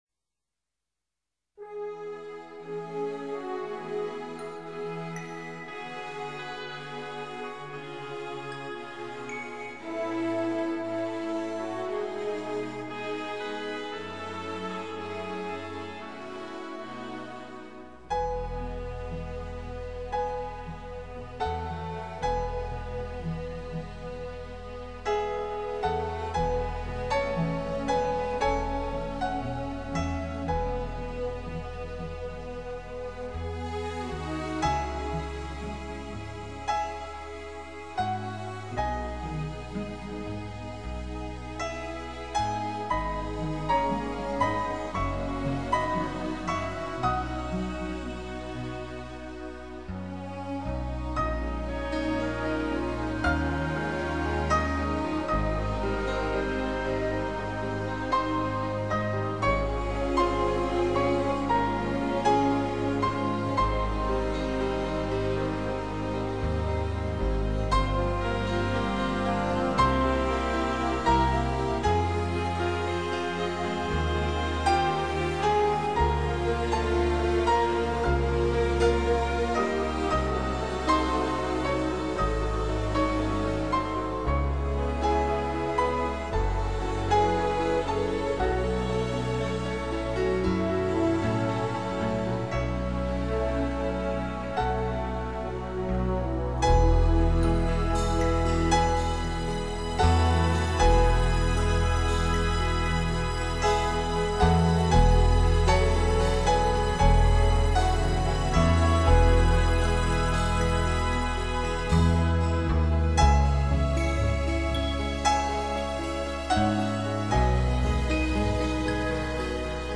手指微妙触及琴键所散发出的音符，充满罗曼蒂克式的醉人芳香将你的情思带入神话中的伊甸园。